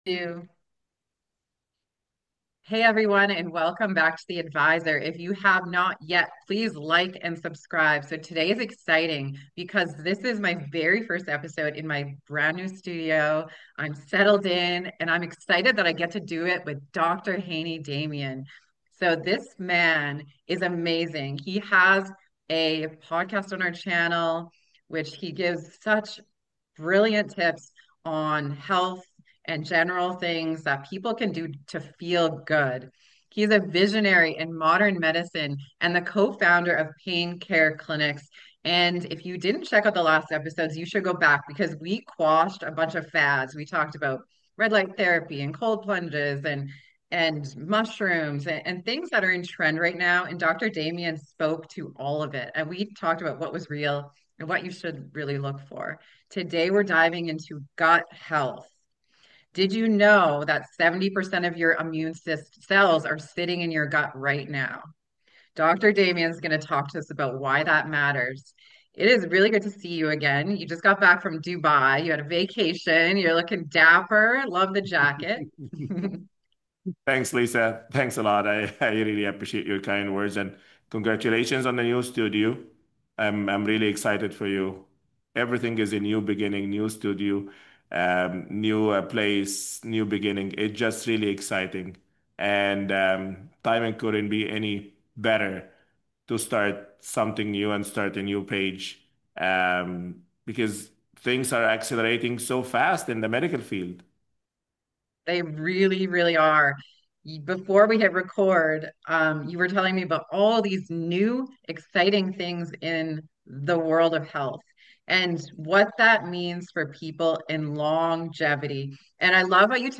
Whether you’re battling fatigue, digestive issues, or just curious about proactive health, this conversation equips you with practical, science‑backed steps to heal your gut and transform your life.